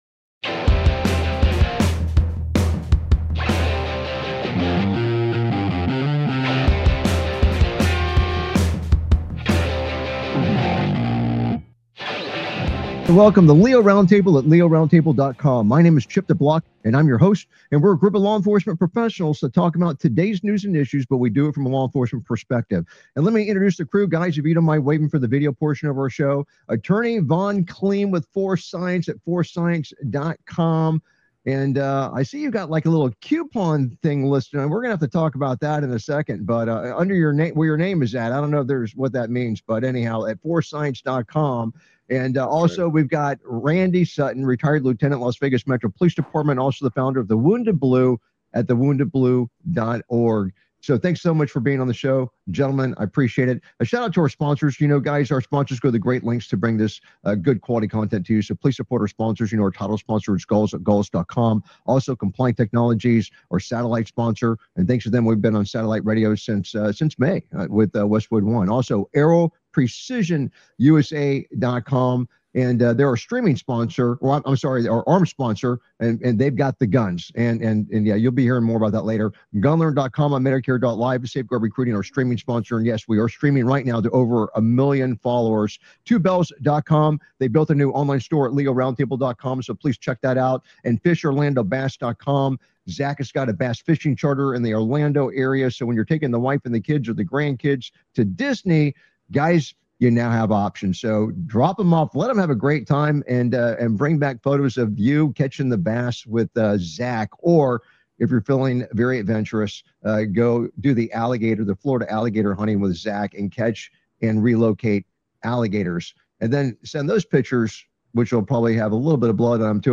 LEO Round Table is a nationally syndicated law enforcement satellite radio talk show discussing today's news and issues from a law enforcement perspective.
Their panelists are among a Who's Who of law enforcement professionals and attorneys from around the country.